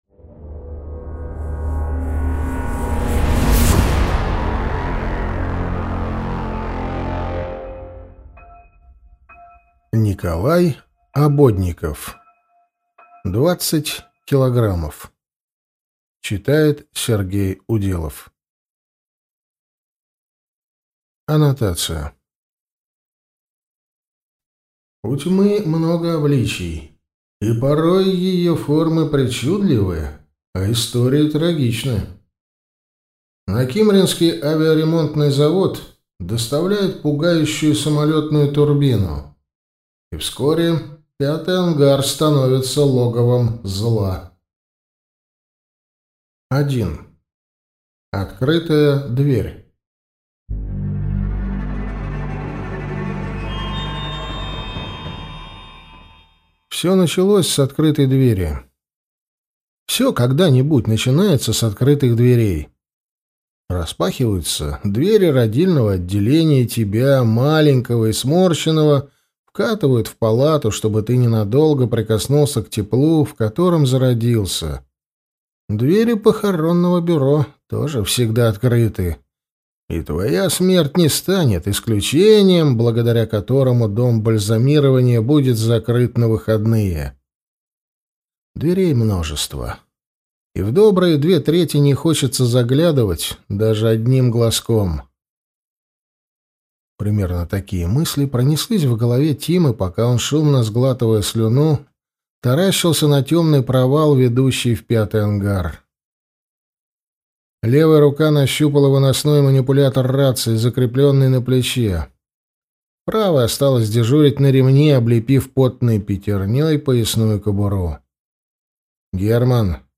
Аудиокнига Двадцать килограммов | Библиотека аудиокниг